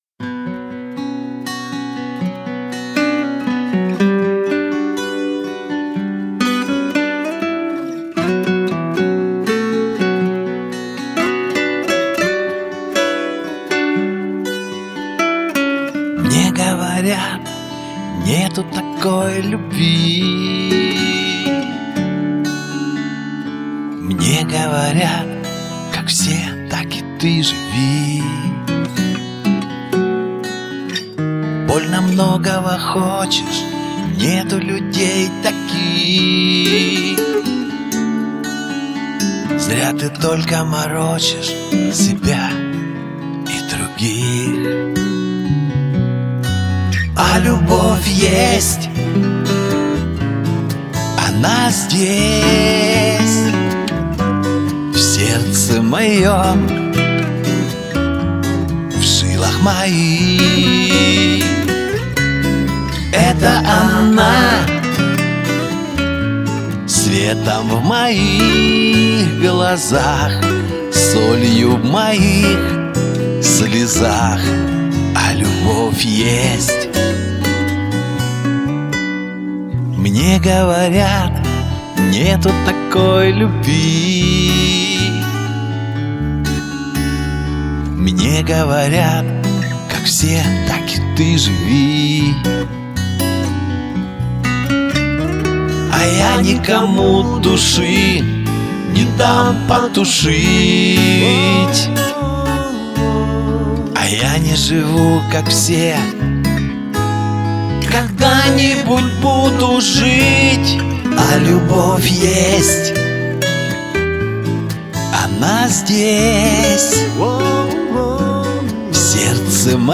Песня у тебя красивенная, звук расчудесный, слушать в удовольствие.